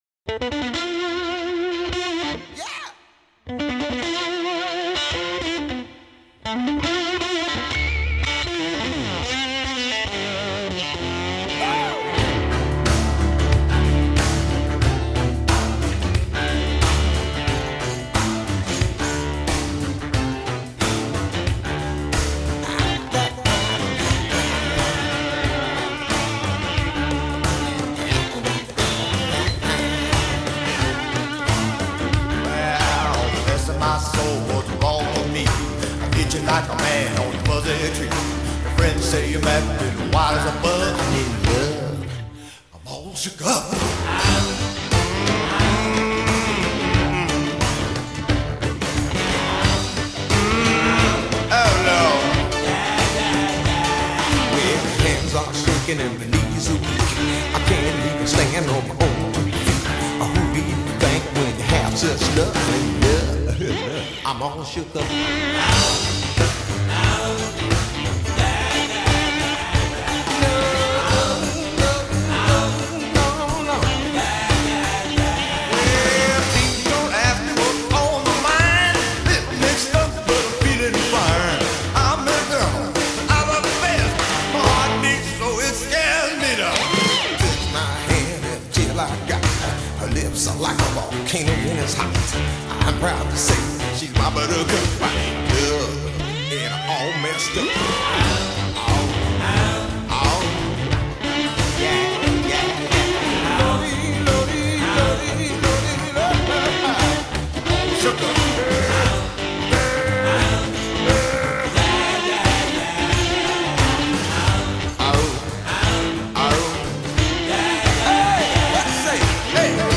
made it all sweaty ‘n’ swampy.